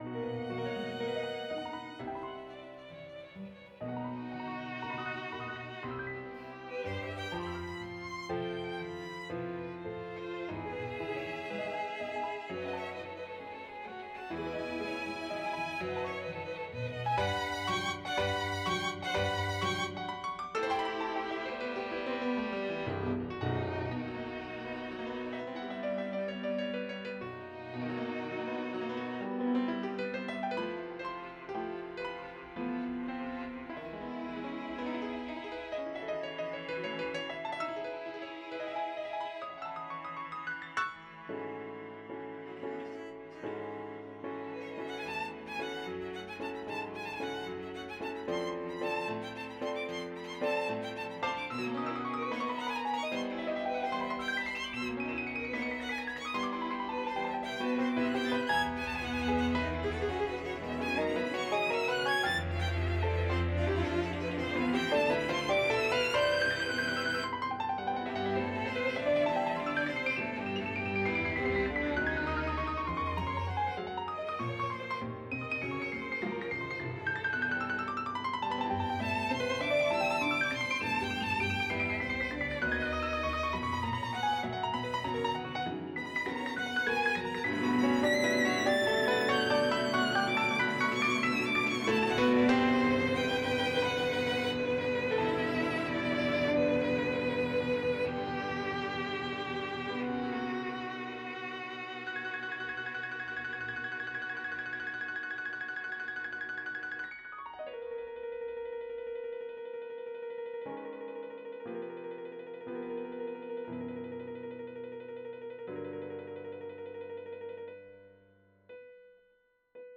Chamber music